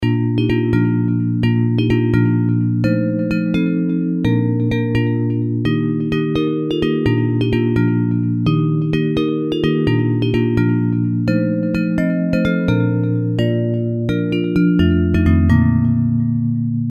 Bells Version